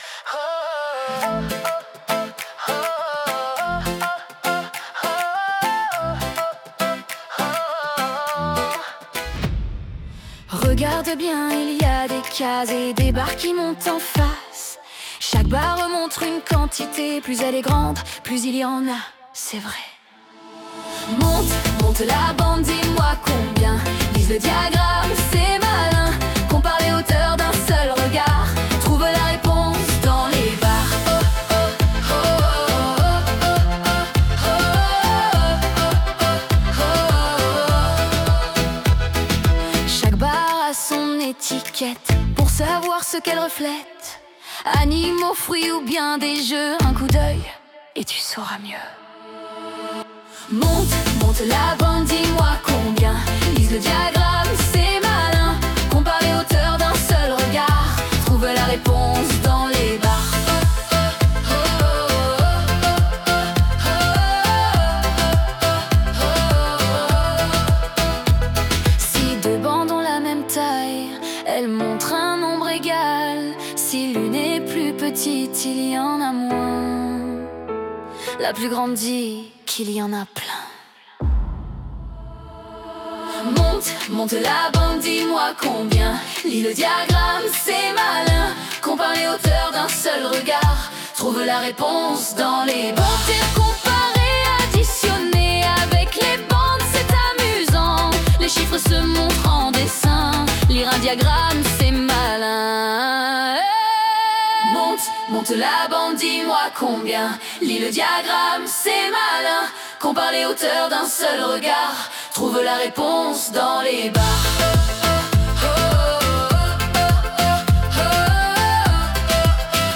Des chansons pour apprendre ! Et pour le plaisir !